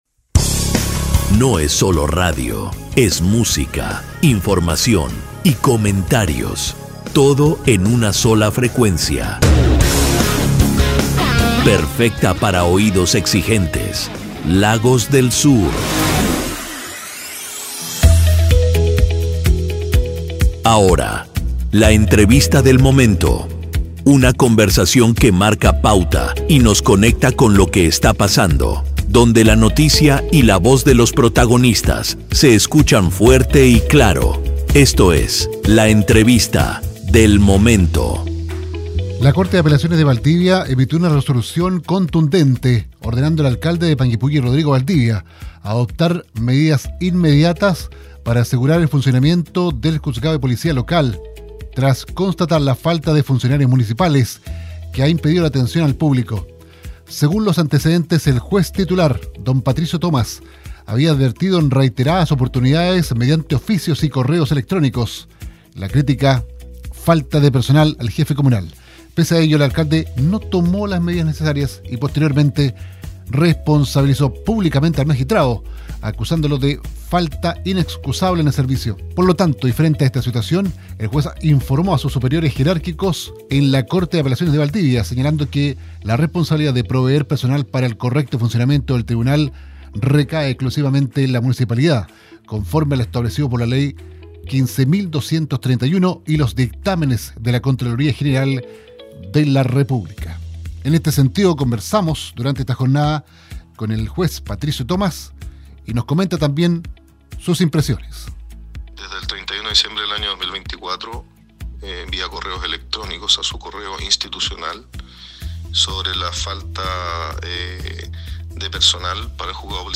entrevista-juez-policia-local.mp3